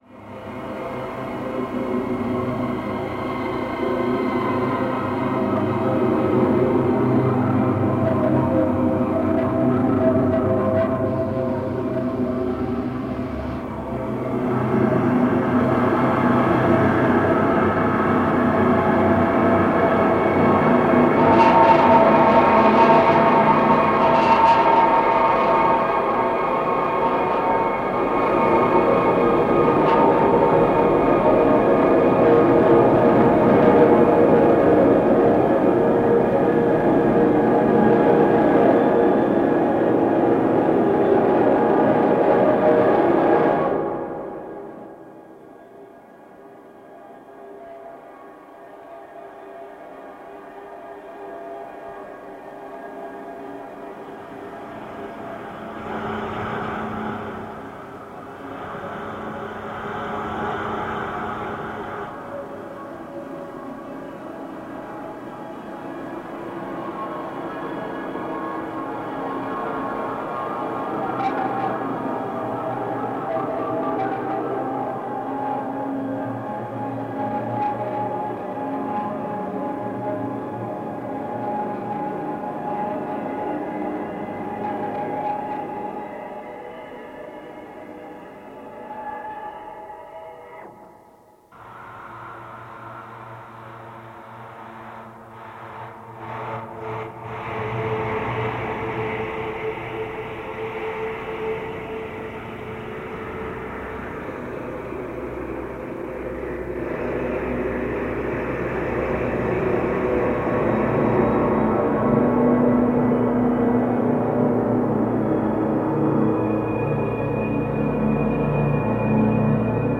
longform electroacoustic composition